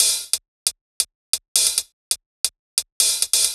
Rogue Hats 135bpm.wav